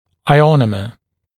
[aɪˈɔnəmə][айˈонэмэ]иономер, ионосодержащий полимер